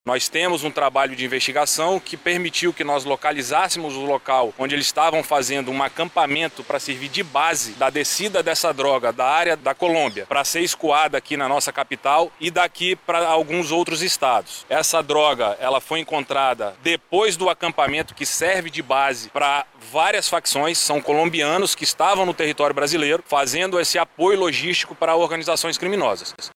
Ainda segundo o delegado-geral, a droga veio da Colômbia para ser distribuída pelos Estados brasileiros.